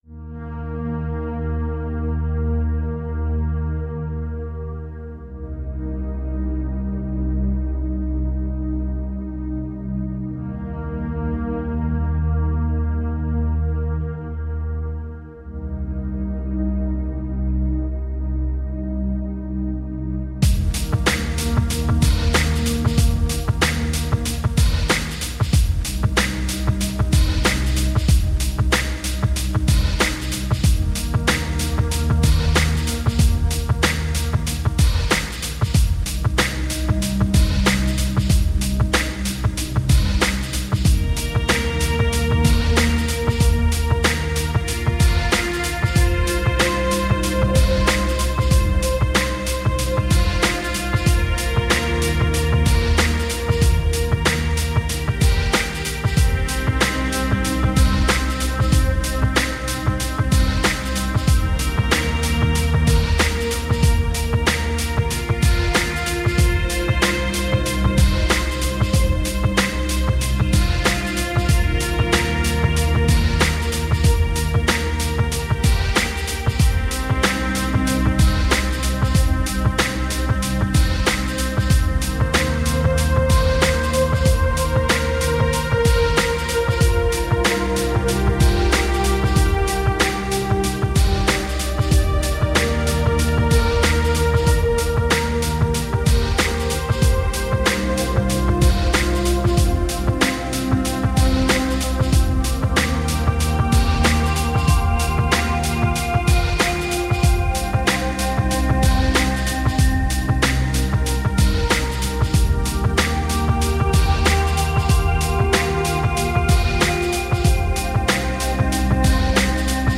Sublime and ethereal electronic music.
Tagged as: Electronica, Techno